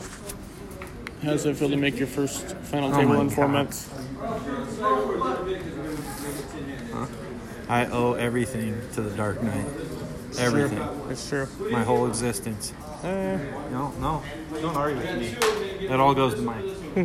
A brief interview: